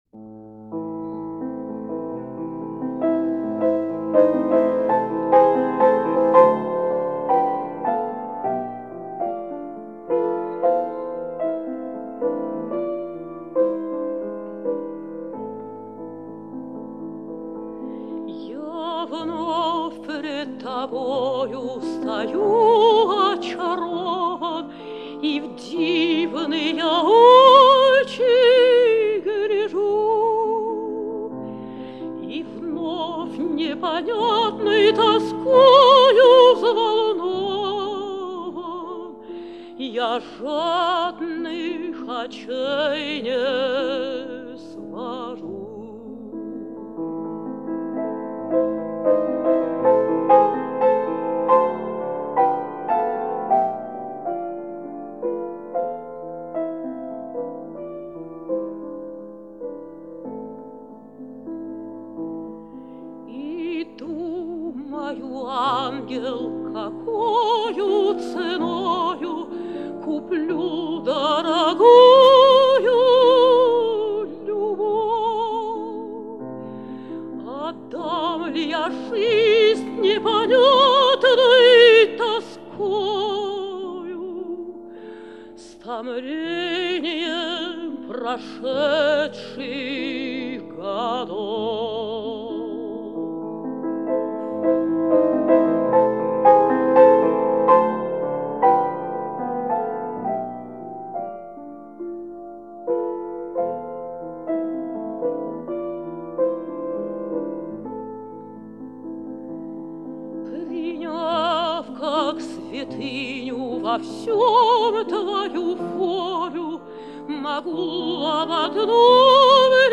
Романс "Я вновь пред тобою стою очарован…" в исполнении Н. Обуховой